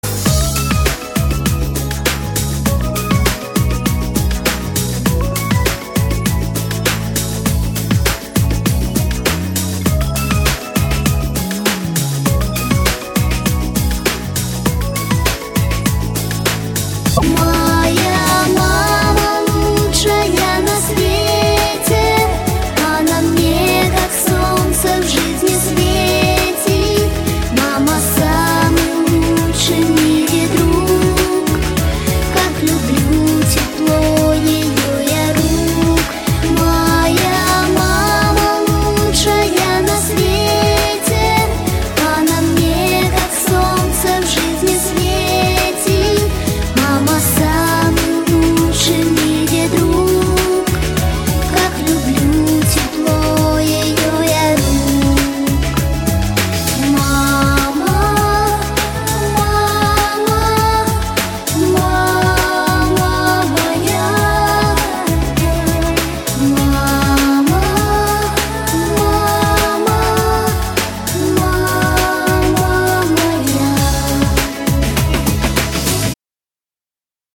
• Качество: 128, Stereo
красивые
романтические